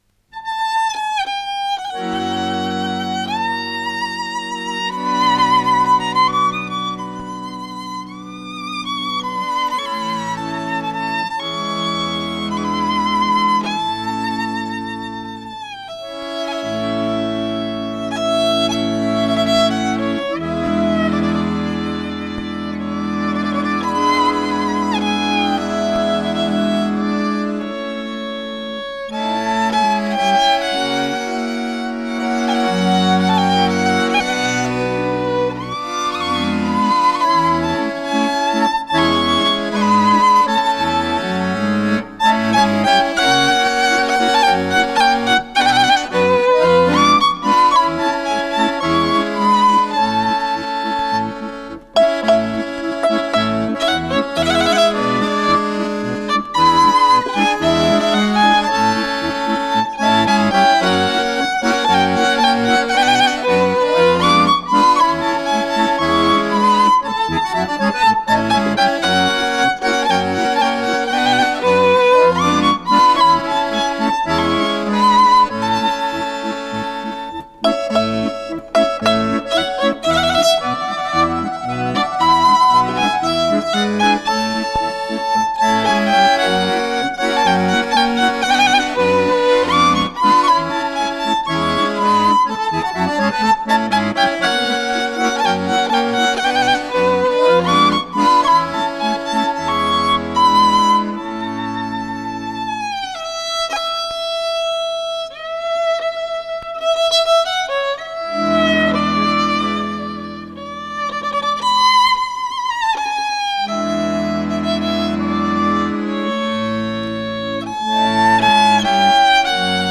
«Zigeuner»-Musik der siebziger Jahre
Live-LP.